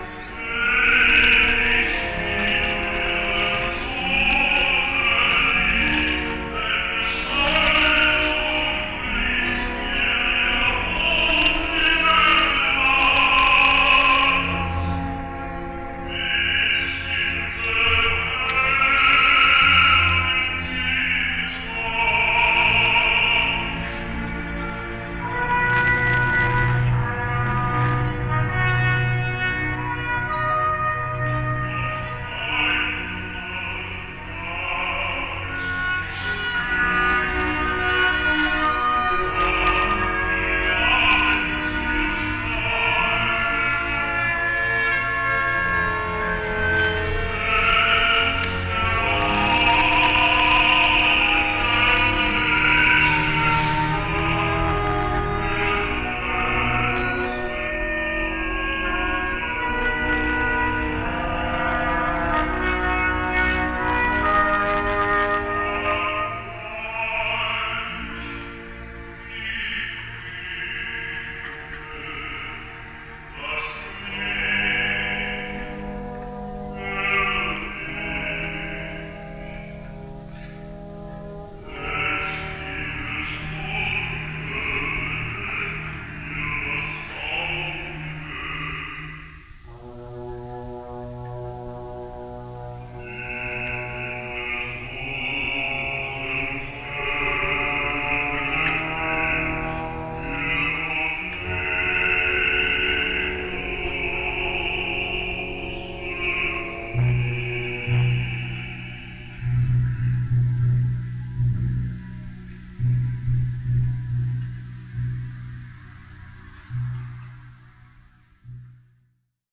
Duca di Mantova (Rigoletto), Gustavus III (Un Ballo in Maschera)  Siegmund (Valkyrie) {concert performance with Essex Symphony Orchestra.}